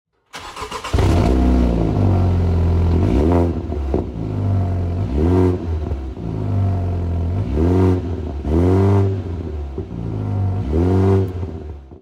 Écoutez le son du moteur !